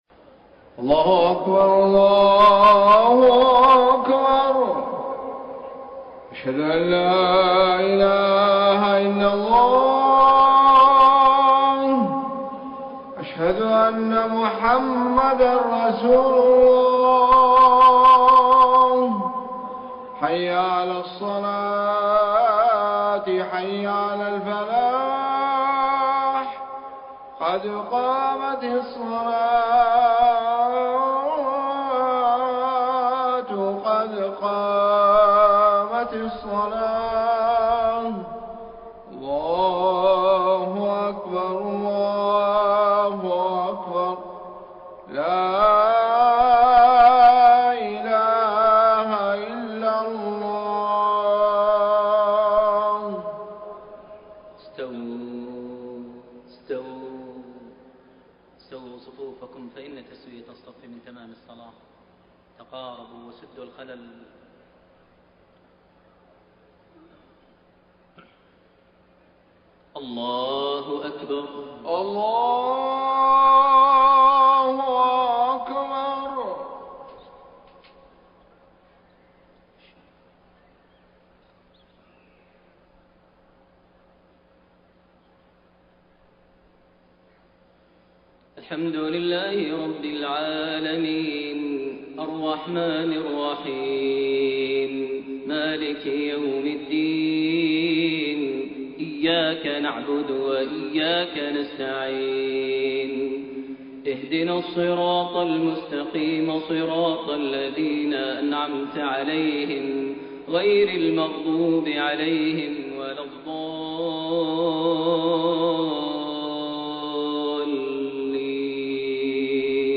Maghrib prayer from Surah At-Tahrim > 1433 H > Prayers - Maher Almuaiqly Recitations